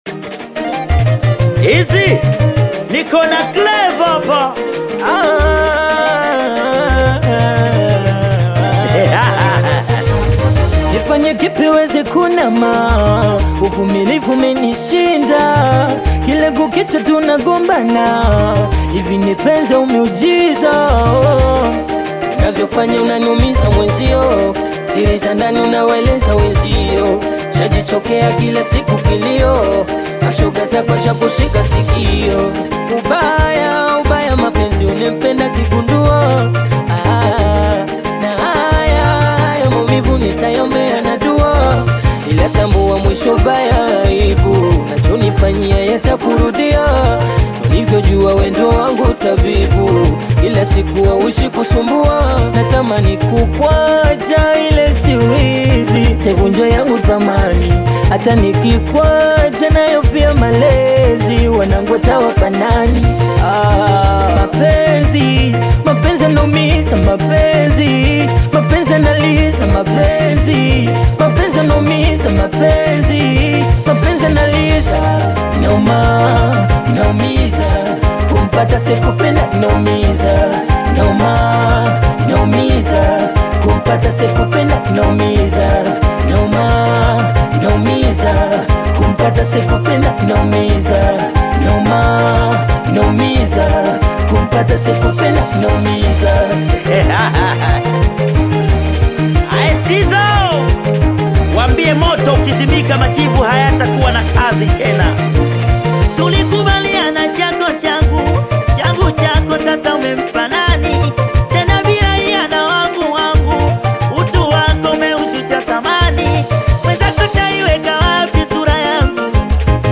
singeli
African Music